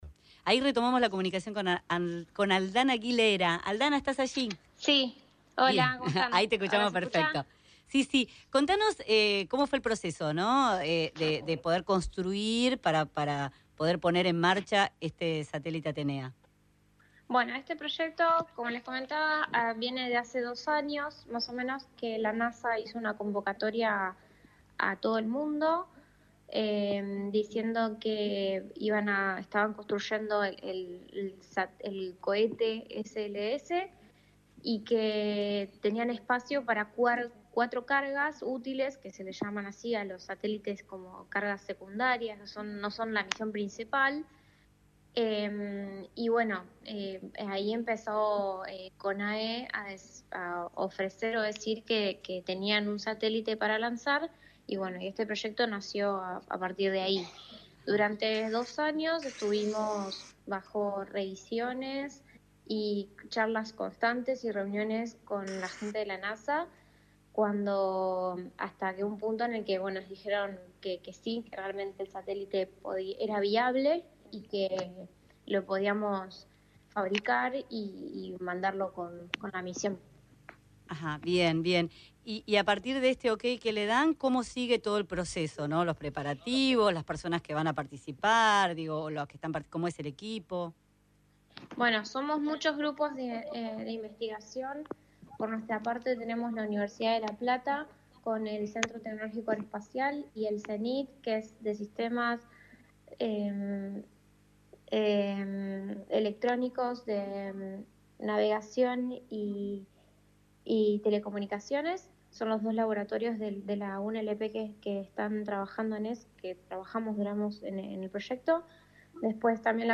Genua, en Radio Mitre, dijo que la misión se acordó en 2025.